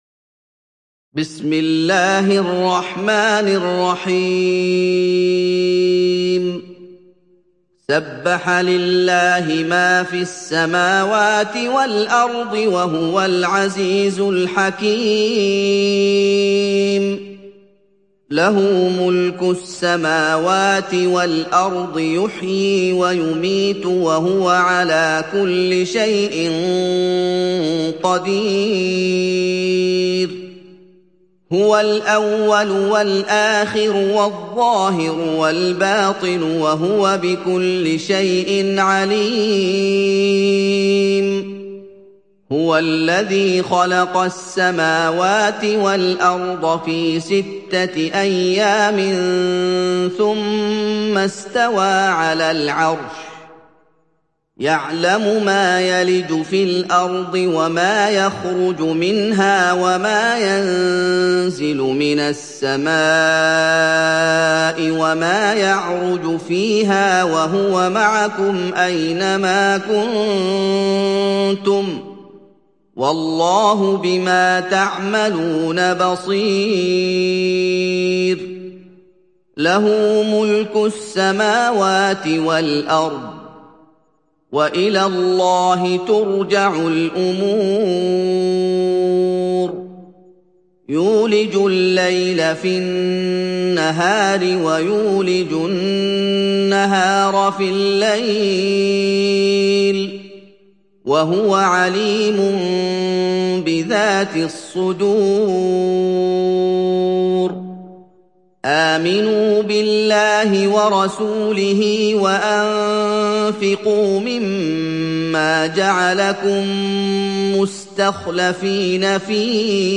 Surat Al Hadid Download mp3 Muhammad Ayoub Riwayat Hafs dari Asim, Download Quran dan mendengarkan mp3 tautan langsung penuh